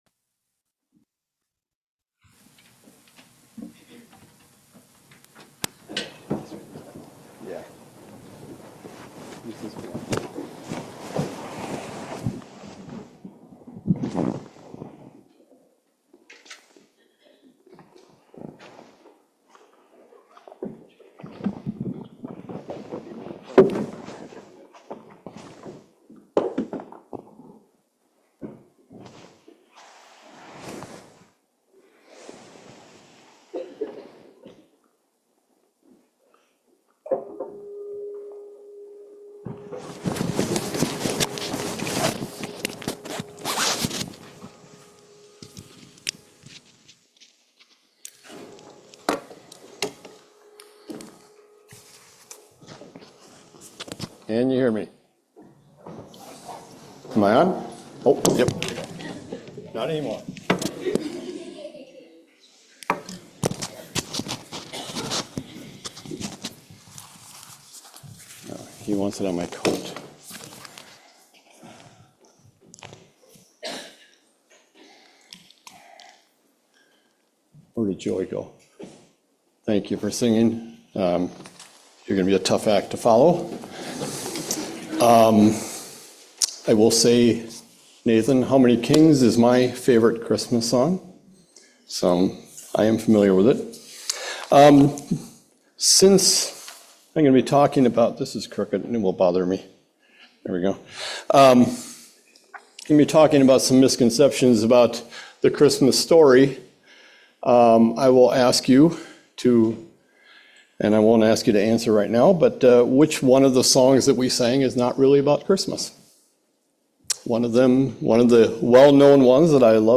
The Wauwatosa Bible Chapel recorded sermons in audio, video, and transcript formats